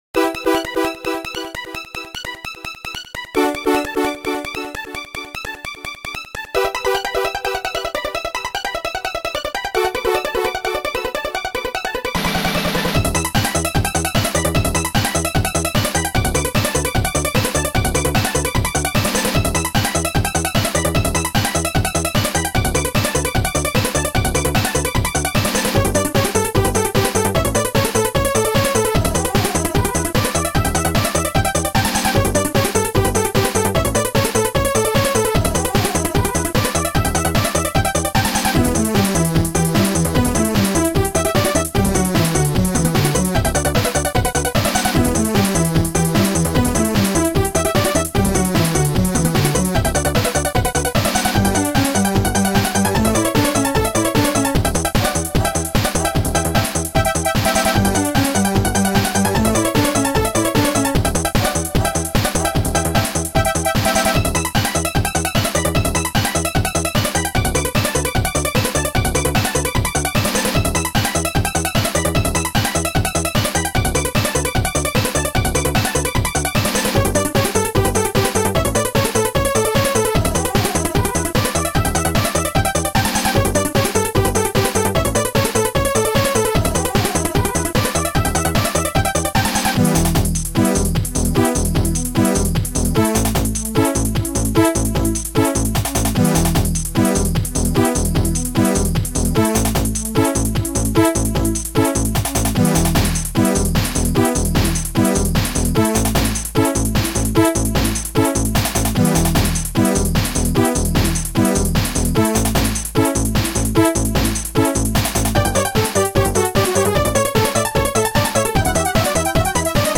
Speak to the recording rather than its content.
Sound Format: Soundtracker 15 Samples